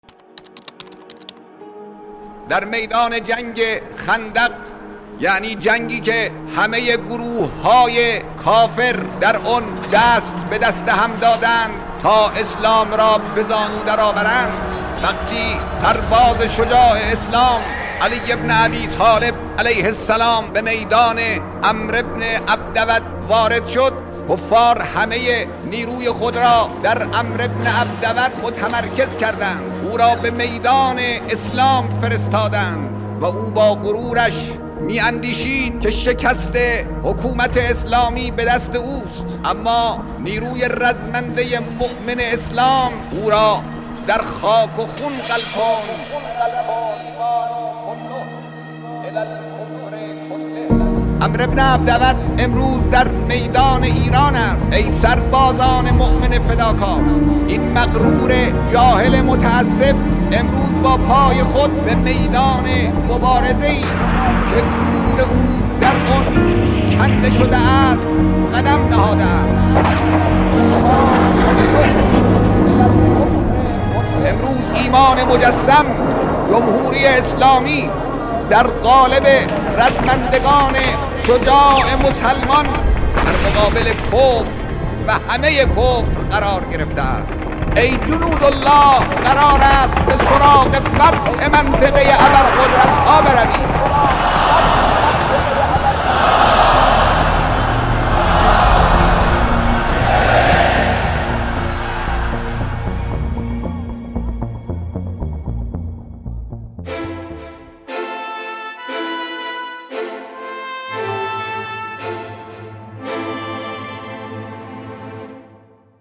نماهنگ | تمامی ایمان در برابر تمامی کفر